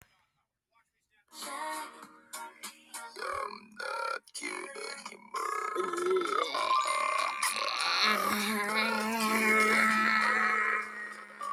voice record